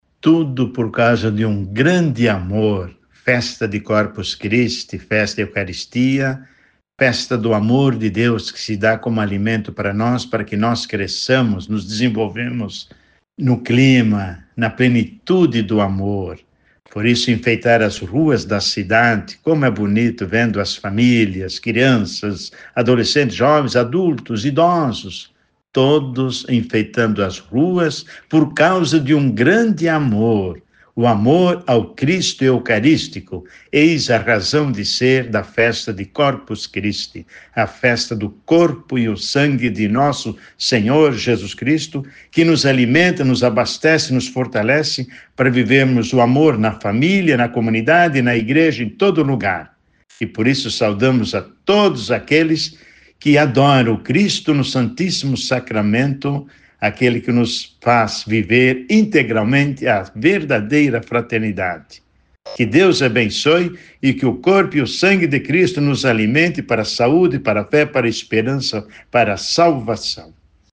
Arcebispo de Maringá fala sobre o verdadeiro sentido de Corpus Christi